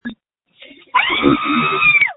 Screams from December 26, 2020
• When you call, we record you making sounds. Hopefully screaming.